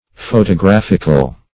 \Pho`to*graph"ic*al\, a. [Cf. F. photographique.]